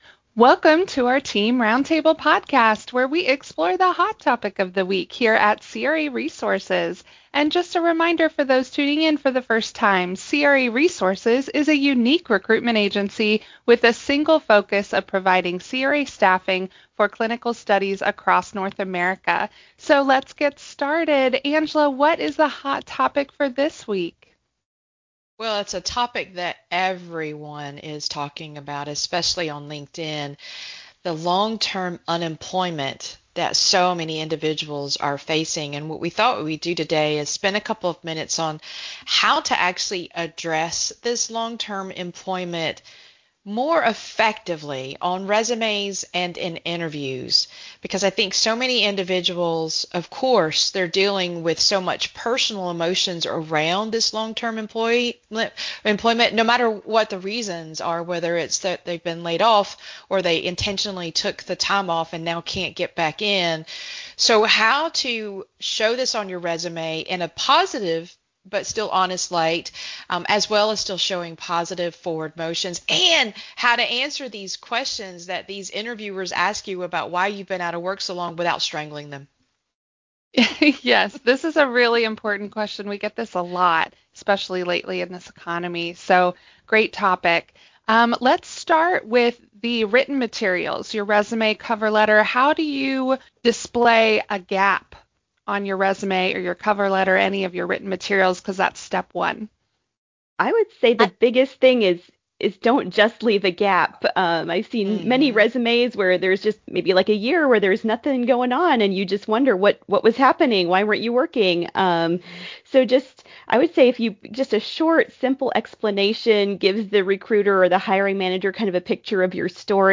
In this week’s roundtable, our team leads an honest and practical conversation about how to handle long-term unemployment on your resume and in your interviews. We also discuss the mindset that could cause you to come across as defensive. The discussion explores how to reshape your story so potential employers view your experience as a strength rather than a setback.